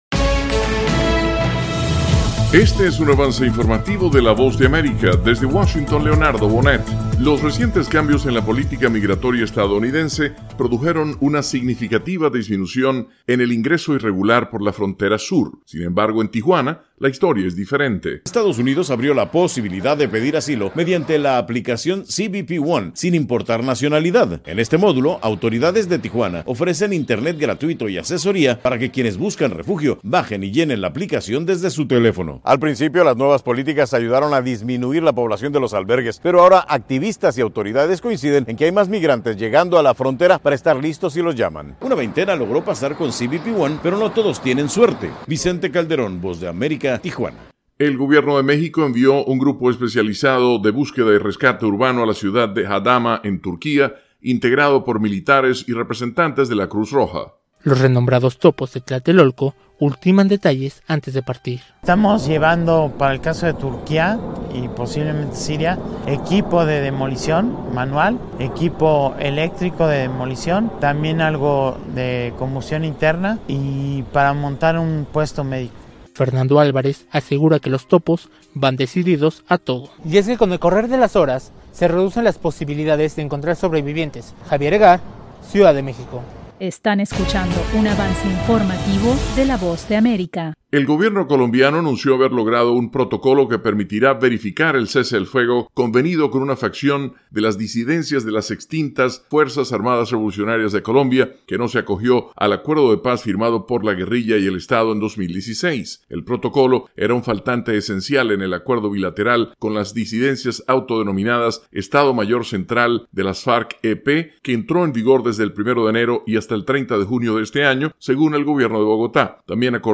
Insertar Avance Informativo 7:00 PM Insertar El código se ha copiado en su portapapeles.